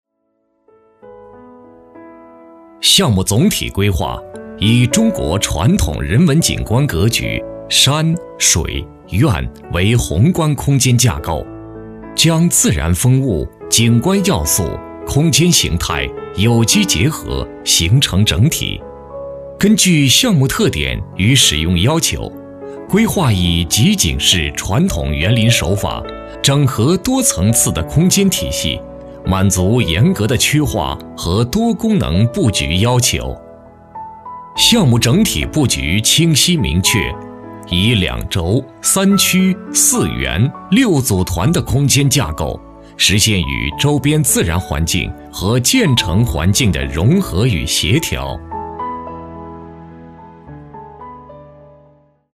专注高端配音，拒绝ai合成声音，高端真人配音认准传音配音
男27